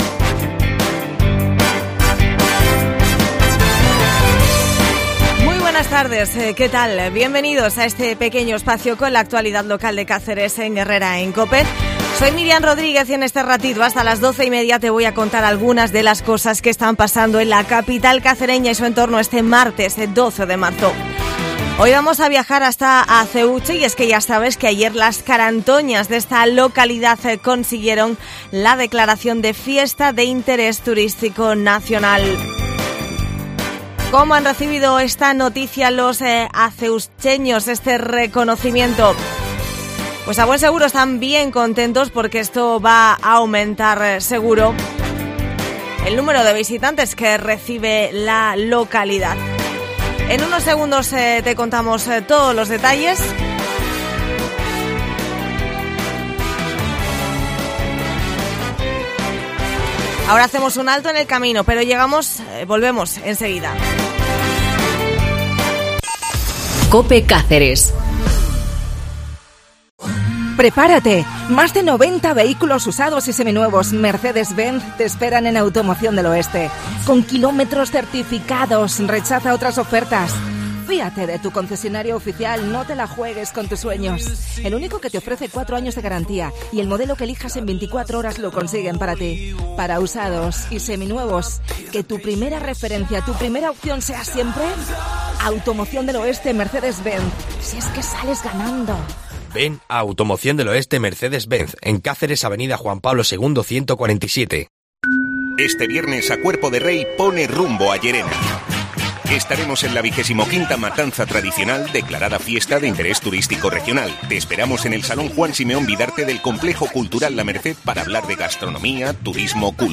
Las Carantoñas de Acehúche, en honor a San Sebastián, ya son Fiesta de Interés Turístico Nacional. En Herrera en Cope hablamos de esta nueva declación con la alcaldesa de la localidad, Obdulia Bueso.